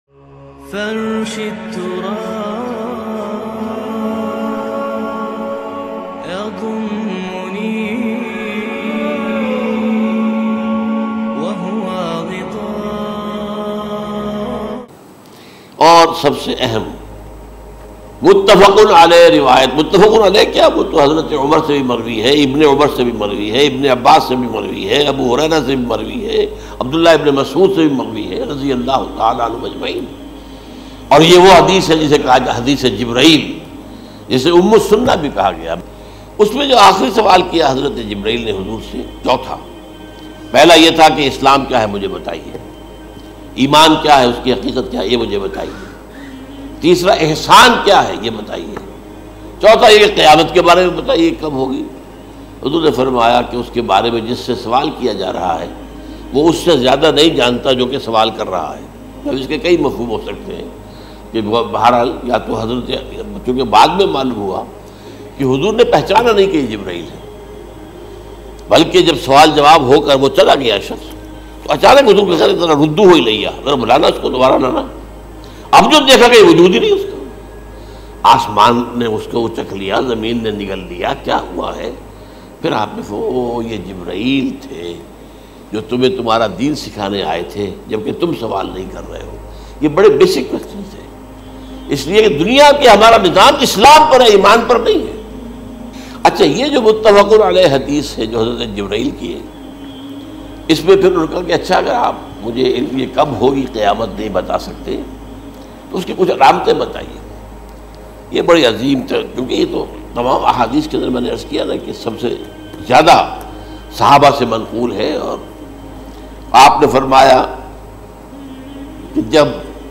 Qayamat Kab Aayegi Dr Israr Ahmed Very Emotional Very Emotional MP3
Dr Israr Ahmed R.A a renowned Islamic scholar.